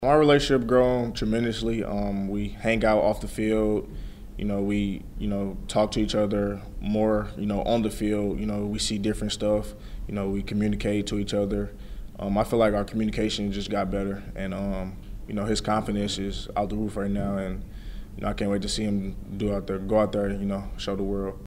Mizzou player cuts from SEC Media Days.